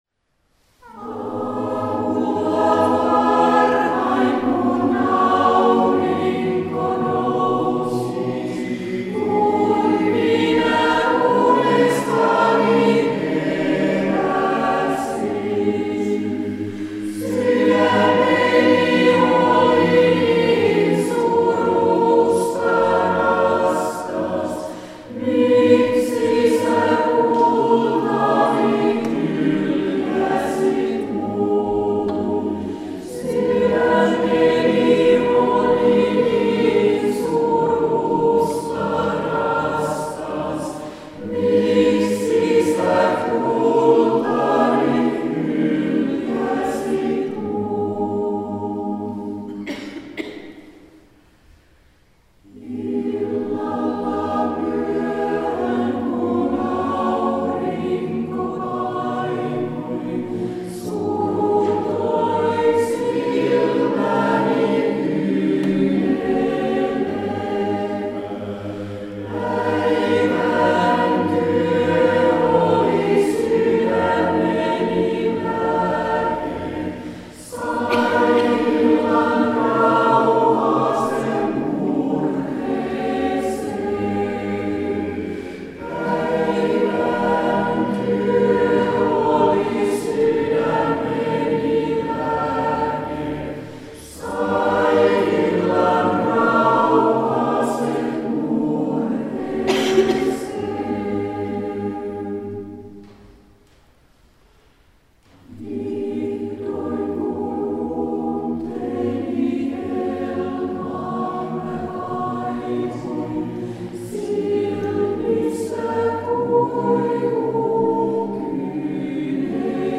I detta projekt har ÅK lämnat bort alla externa element och sjunger inhemska körklassiker a cappella.